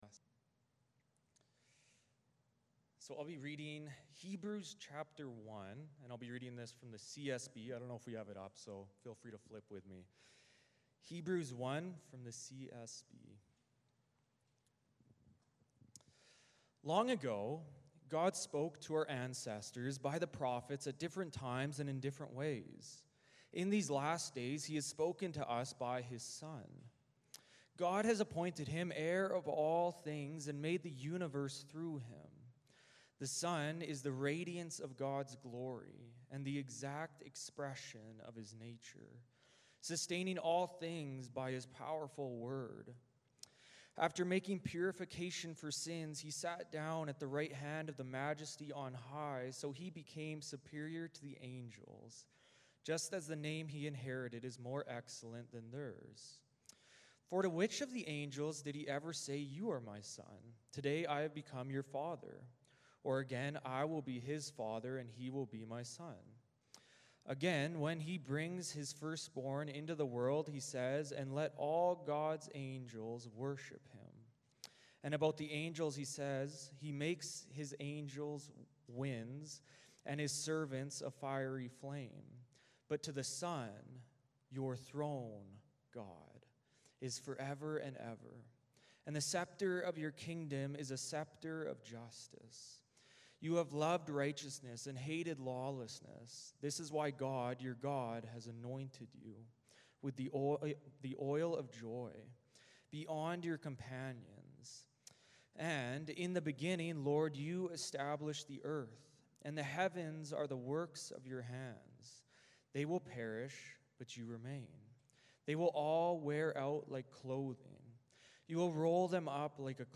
Service Type: Sunday Morning Service Passage